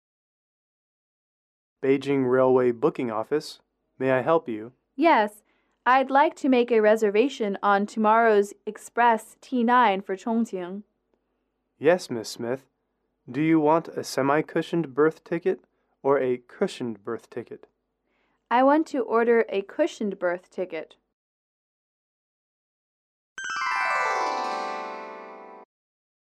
英语主题情景短对话08-3：订火车票